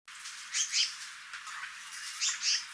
54-2黑頸藍鶲柴山2011dec24.mp3
黑枕藍鶲(台灣亞種) Hypothymis azurea oberholseri
高雄市 鼓山區 柴山
錄音環境 次生林